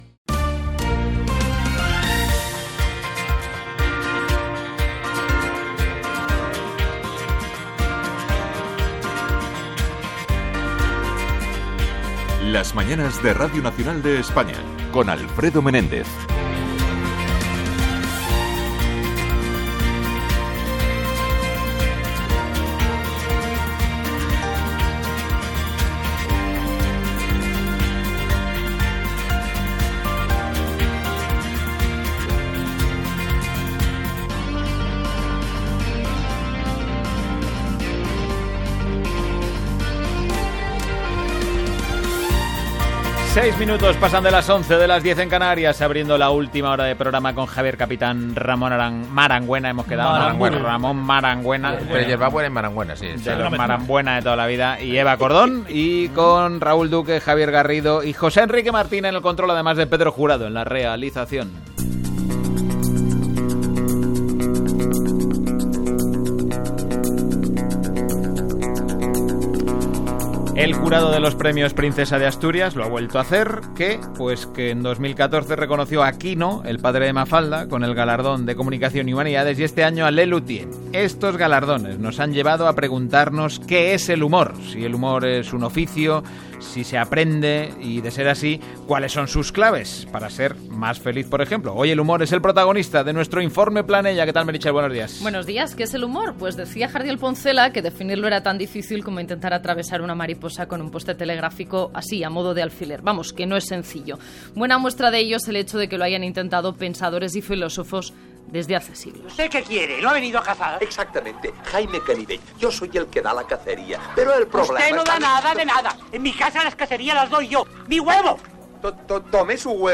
Sintonia del programa
Gènere radiofònic Info-entreteniment